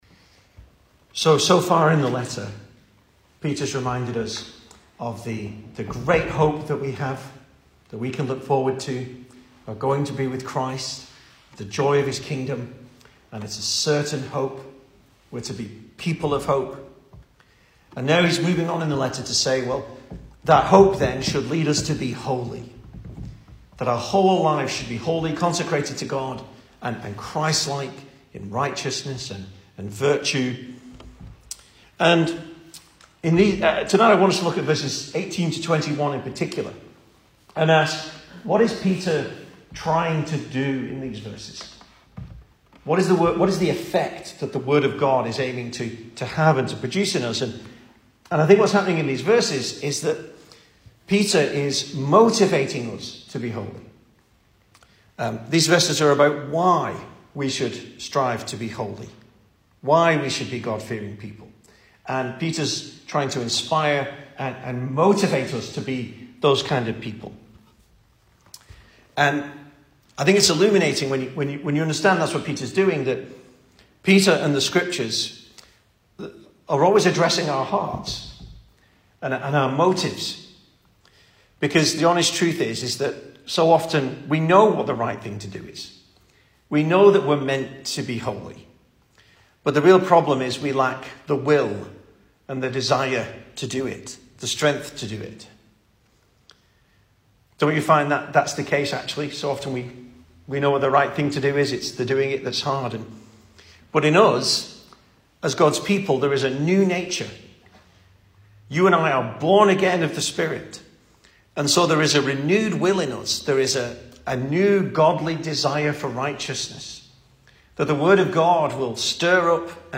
2023 Service Type: Weekday Evening Speaker